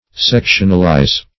sectionalize - definition of sectionalize - synonyms, pronunciation, spelling from Free Dictionary
Sectionalize \Sec"tion*al*ize\, v. t.